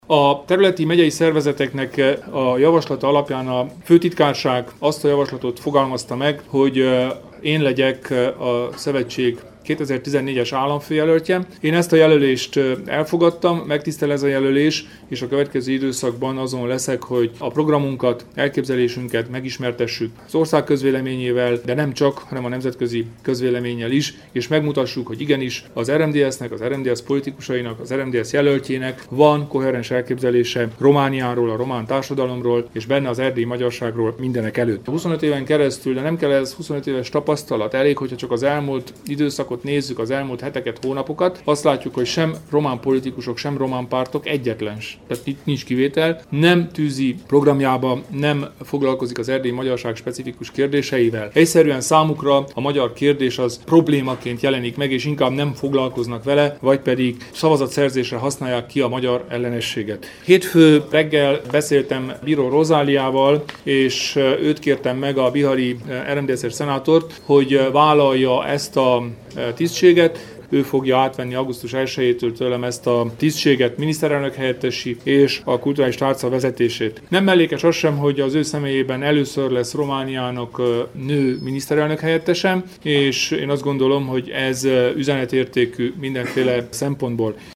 Itt meghallgatható Kelemen Hunor nyilatkozata.